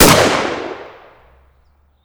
PNRoyalRifleSound.wav